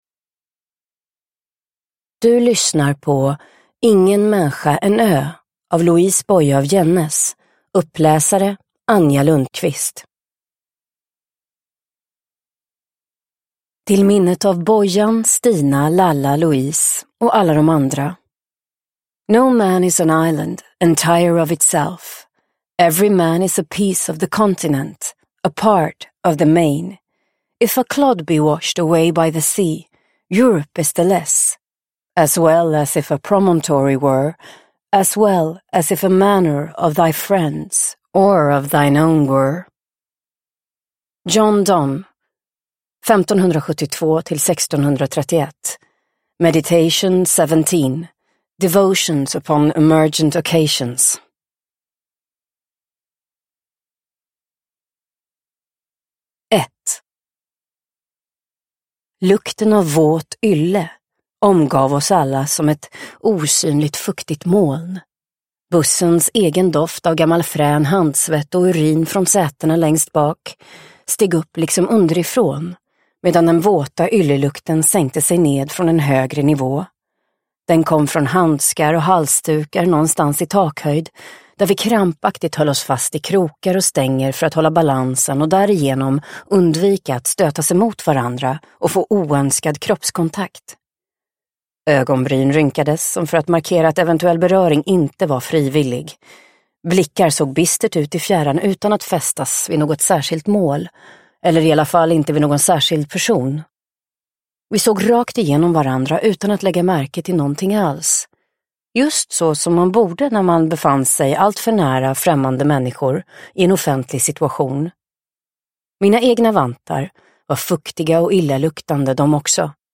Uppläsare: Anja Lundqvist
Ljudbok